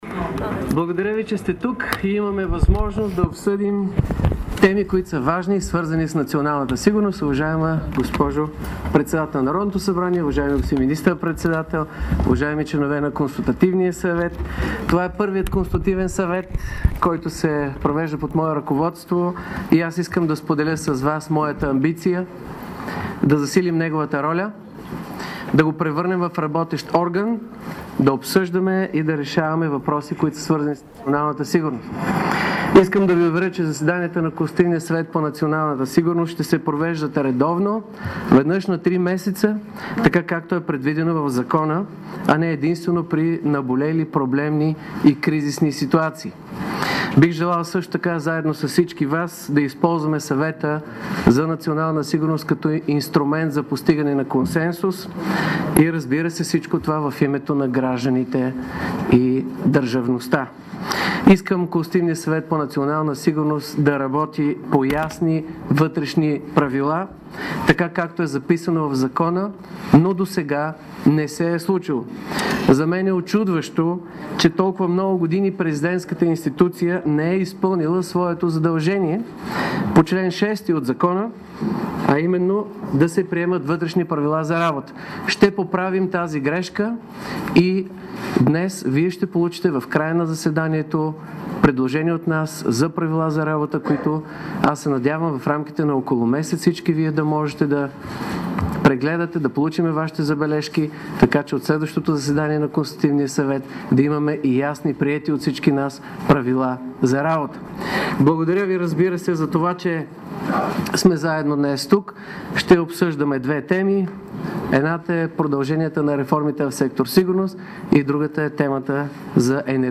Цялото изказване на Росен Плевнелиев в началото на първото заседание на Консултативния съвет по национална сигурност в рамките на неговия мандат чуйте ТУК